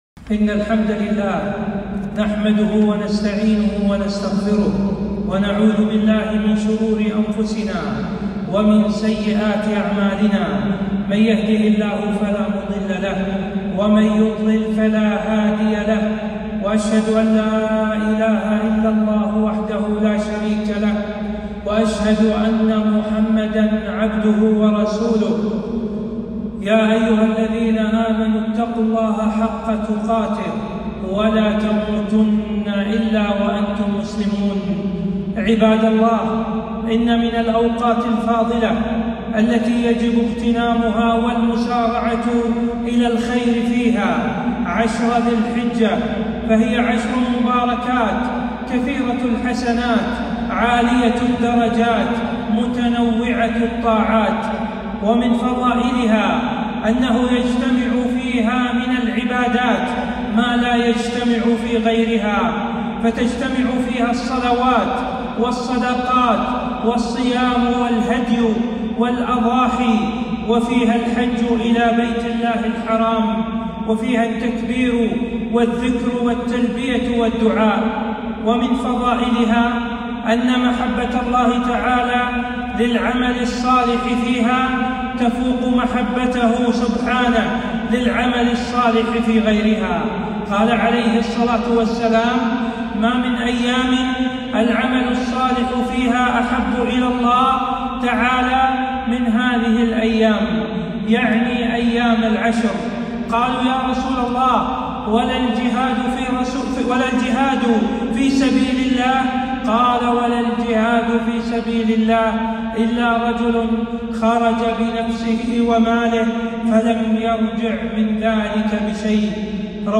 خطبة - فضل العشر ذي الحجة ويومي عرفة والنحر وأحكام الأضحية